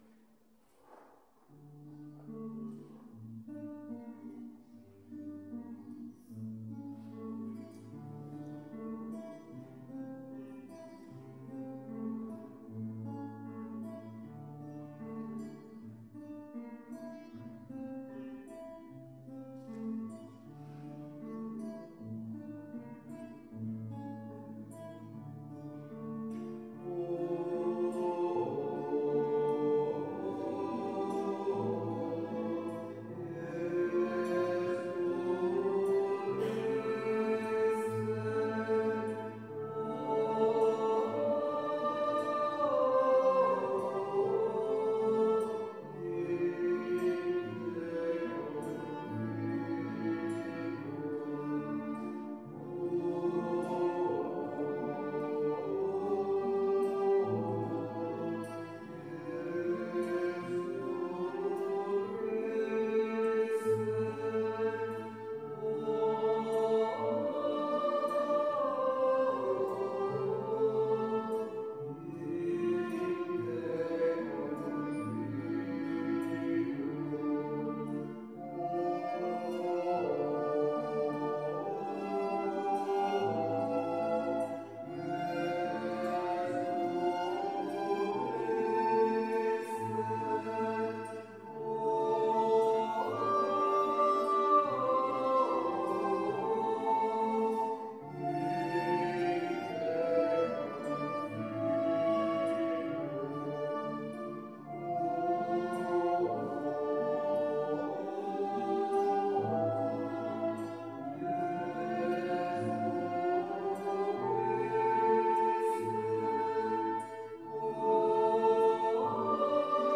Pregària de Taizé
Església de Santa Anna - Diumenge 25 d'octubre de 2015
Vàrem cantar...